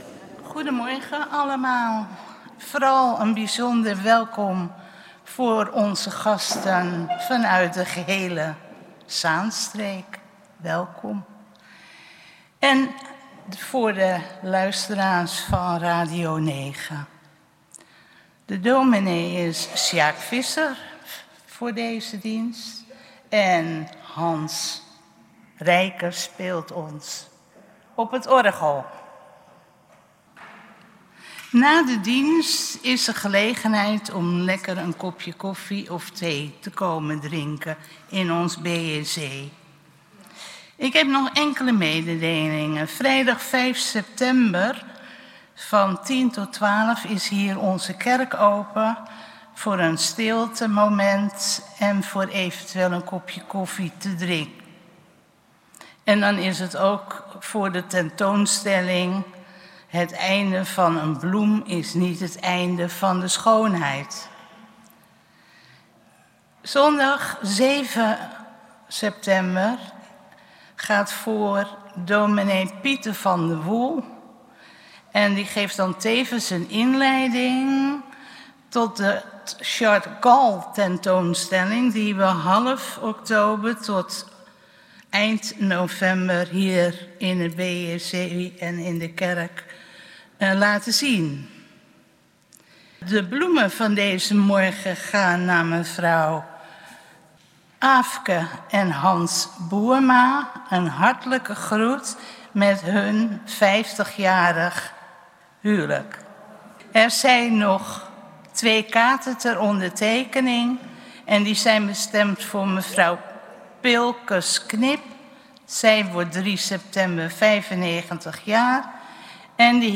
Kerkdienst geluidsopname